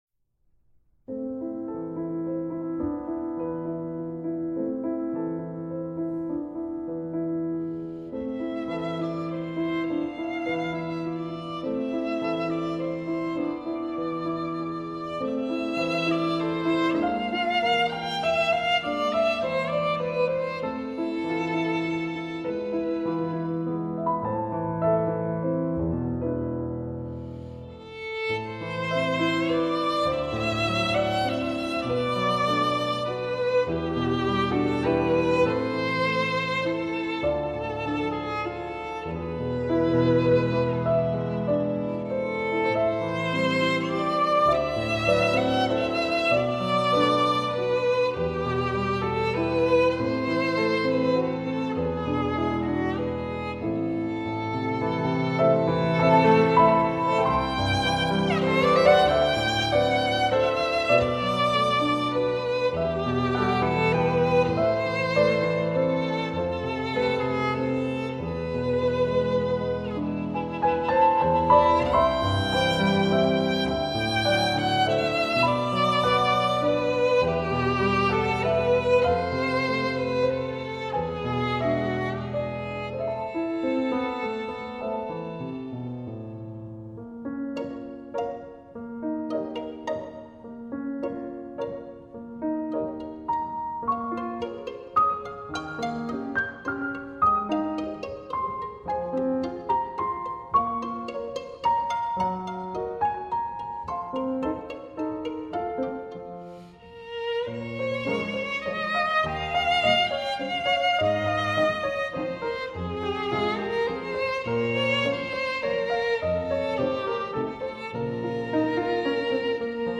（含圣乐音频）